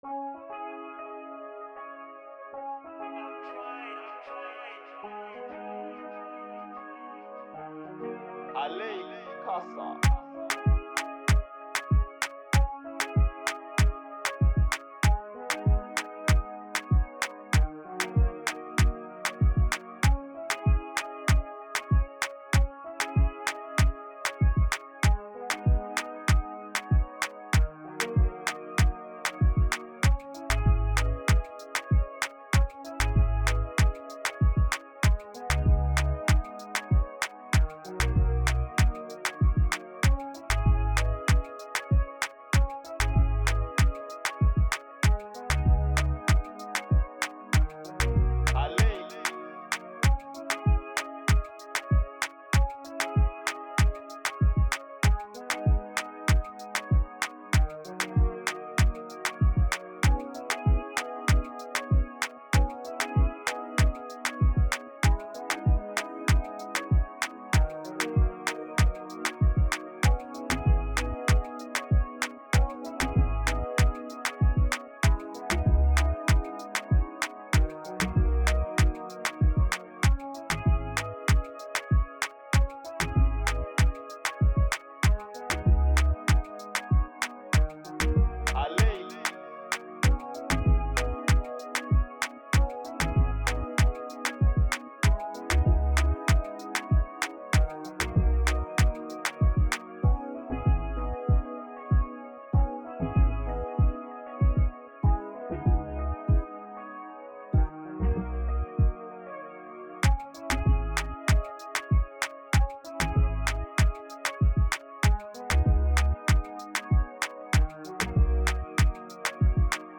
free beat instrumental